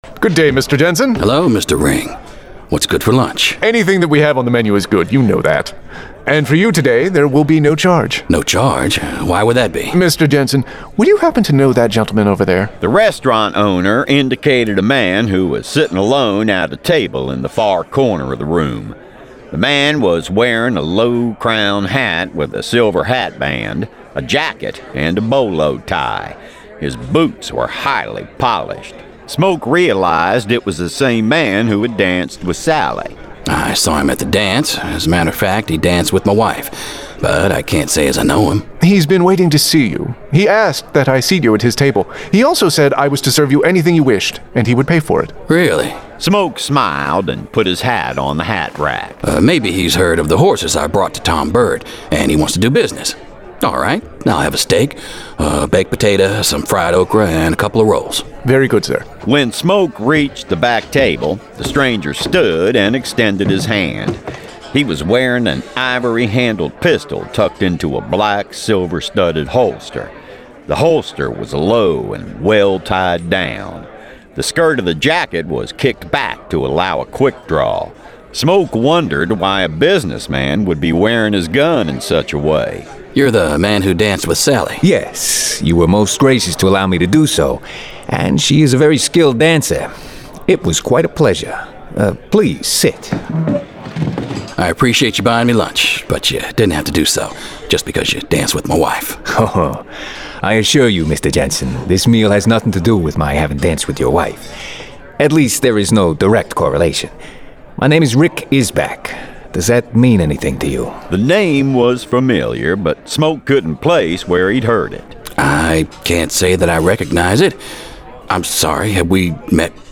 Full Cast. Cinematic Music. Sound Effects.
[Dramatized Adaptation]
Genre: Western